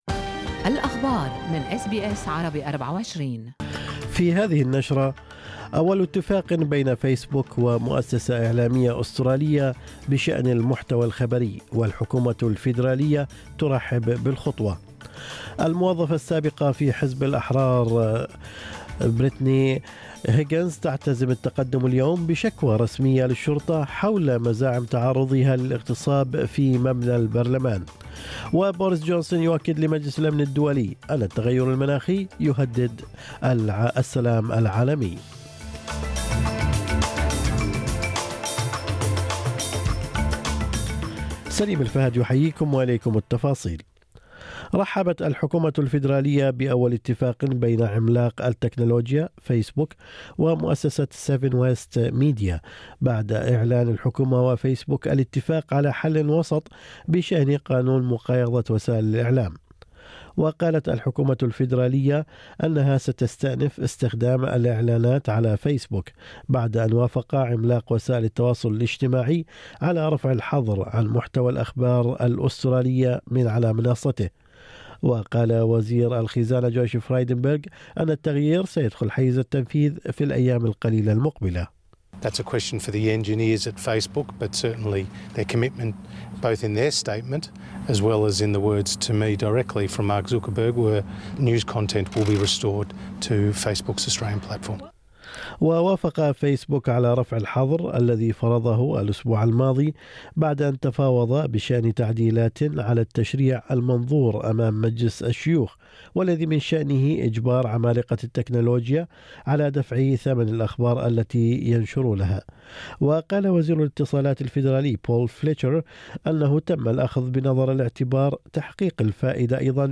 نشرة أخبار الصباح 24/2/2021